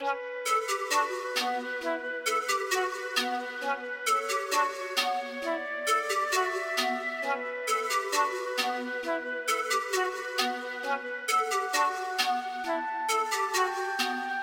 埋葬式的鼓声 4 Bars
描述：最近一直在感受车库的氛围试图通过音调降低/切碎的踢腿、手指扣和hi hats来实现那种空旷/滑稽的声音。
标签： 133 bpm Garage Loops Drum Loops 1.21 MB wav Key : Unknown
声道立体声